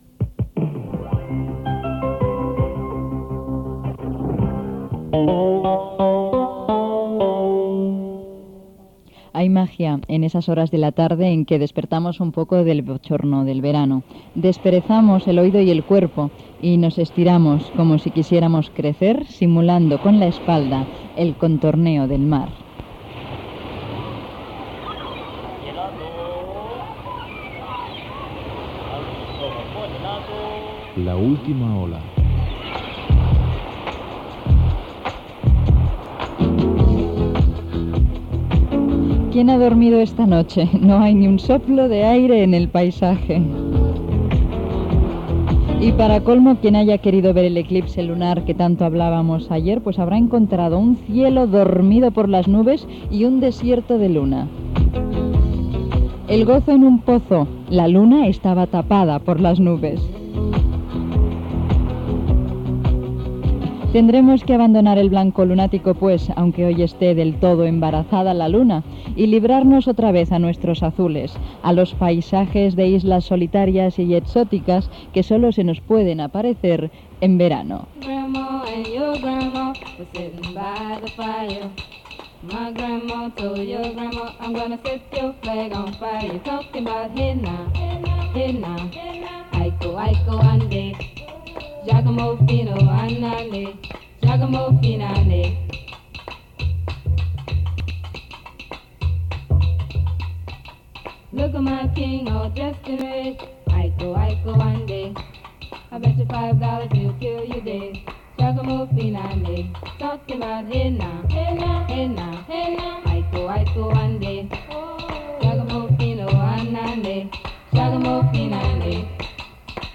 Indicatius musicals de la Cadena RATO i Cadena Catalana, frase inicial, careta, comentari sobre l'eclipsi de lluna. Tema musical, la ciutat de Barcelona, freqüències de les emissores de Vilanova i la Geltrú i Barcelona, sumari.
Gènere radiofònic Entreteniment